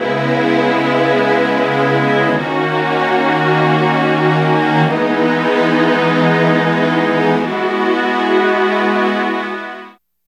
14 STR CHD-R.wav